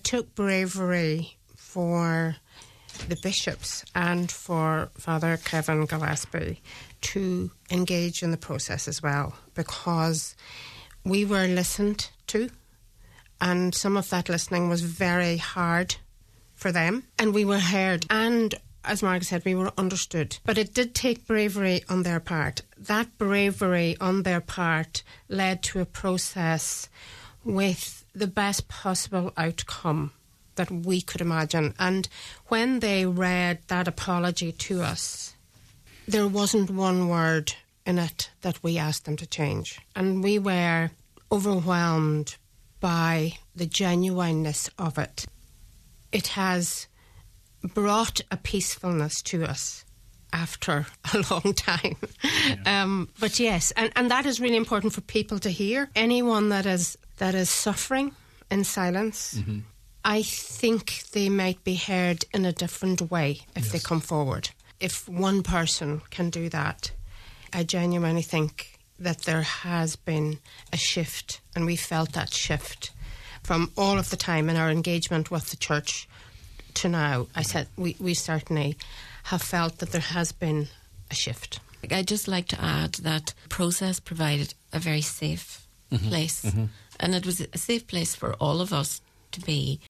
On today’s Nine til Noon Show, the sisters said they finally felt heard by Church.